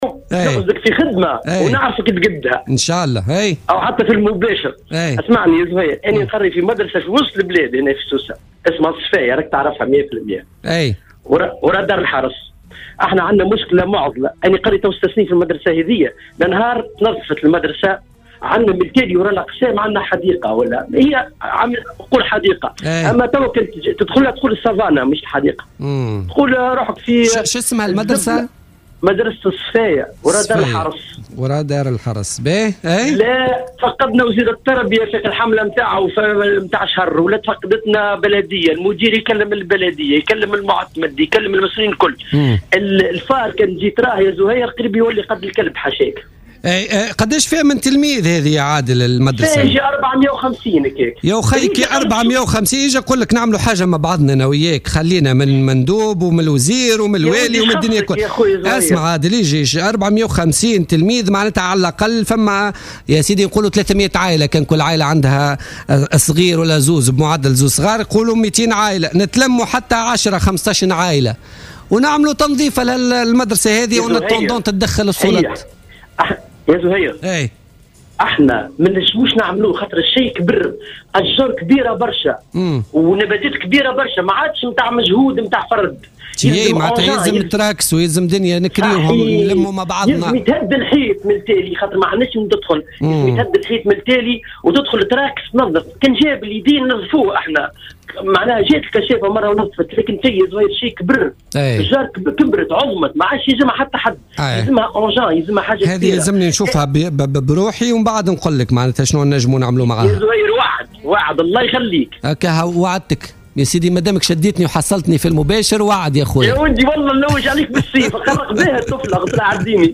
وتدخلت النائبة عن ولاية سوسة في برنامج بوليتيكا على "الجوهرة أف أم" على اثر نداء تقدم به معلم بمدرسة الصفاية بنفس البرنامج، طالب فيه بالتدخل السريع لتنظيف المدرسة التي يؤمها نحو 400 تلميذ كما توجه بنداءه إلى النائبة زهرة ادريس بوصفها سيدة اعمال، لمساعدتهم على تنظيف المدرسة عبر ارسال "تراكس" وهو ما استجابت له السيدة ادريس على الفور عبر "الجوهرة أف أم".